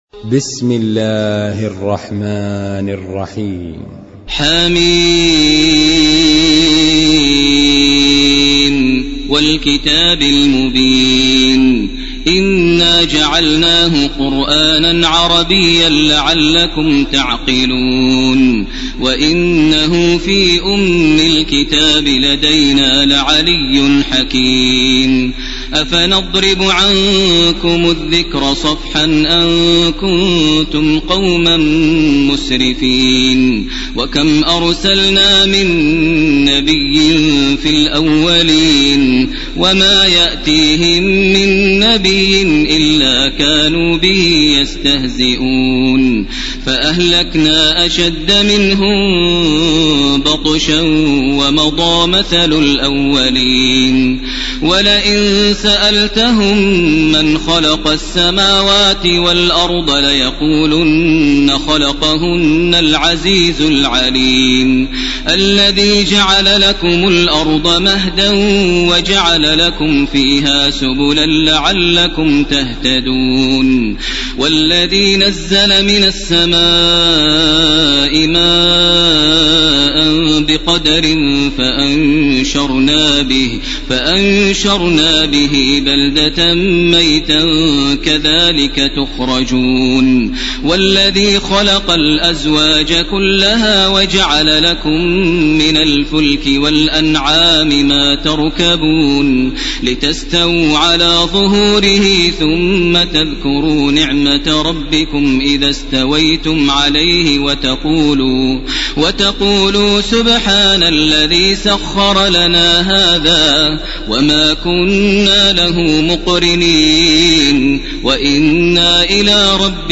ليلة 24 من رمضان 1431هـ سورة الزخرف وسورة الدخان كاملة. > تراويح ١٤٣١ > التراويح - تلاوات ماهر المعيقلي